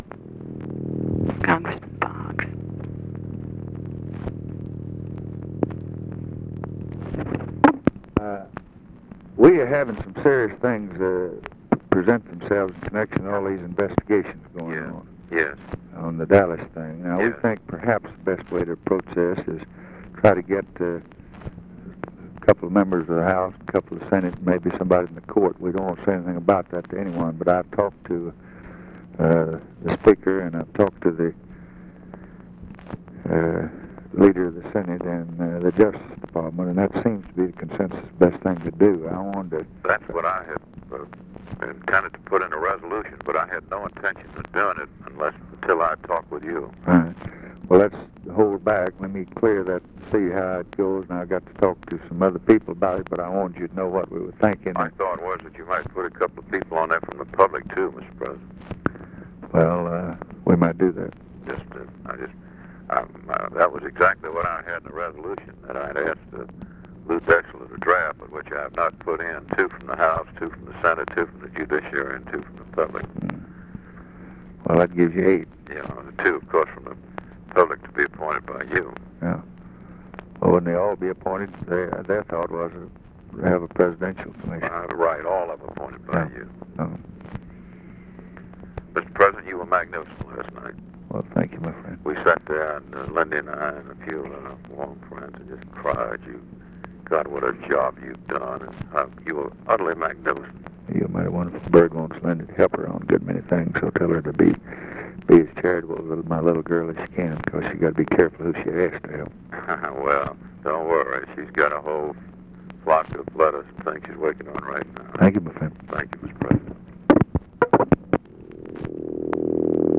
Conversation with HALE BOGGS, November 29, 1963
Secret White House Tapes